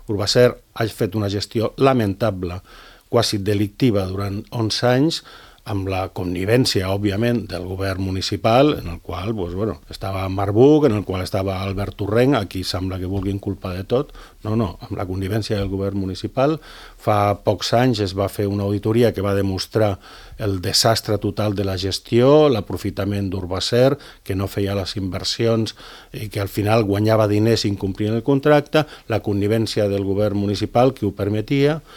Ho ha exposat el portaveu dels comuns, Sebastián Tejada, a l’ENTREVISTA POLÍTICA de Ràdio Calella TV.